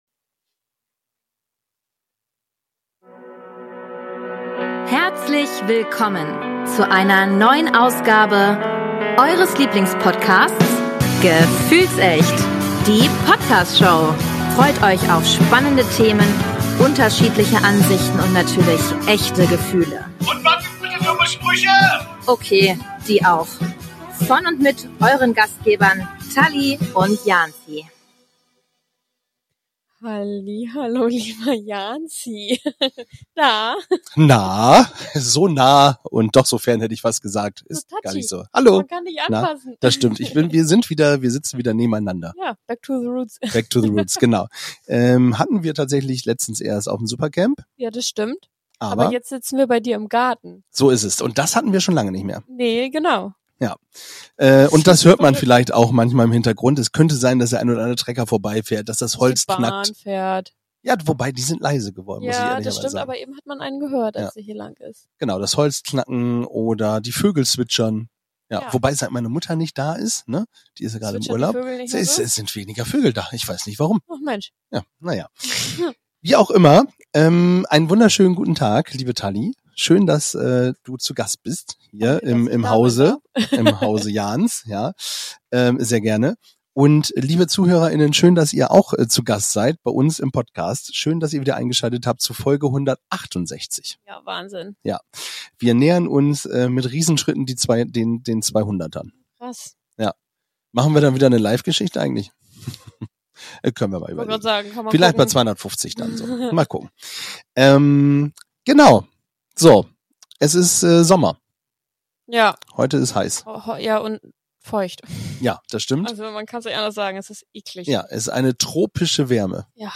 Geradezu beschwingt von guter Laune und einem Glas Wein diskutieren sie die Frage, wie Künstliche Intelligenz unseren Alltag und unsere Beziehungen beeinflusst.